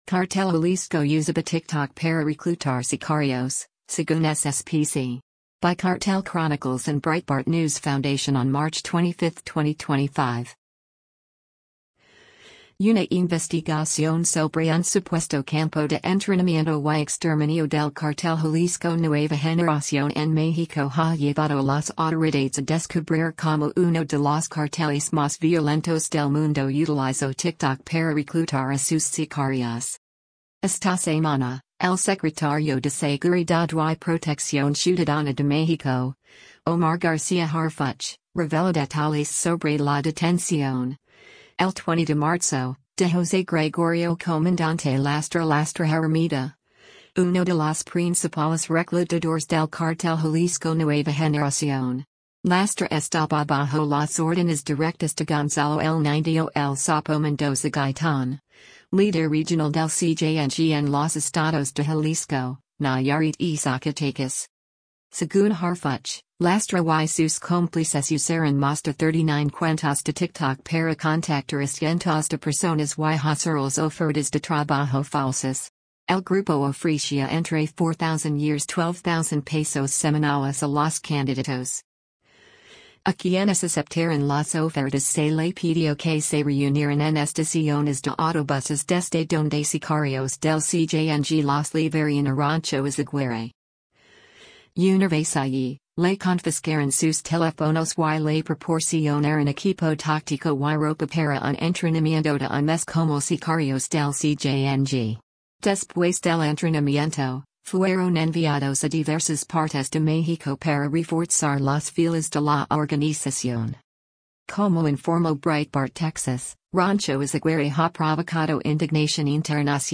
Mexico's Public Security Secretary Omar Garcia Harfuch talks about the investigation into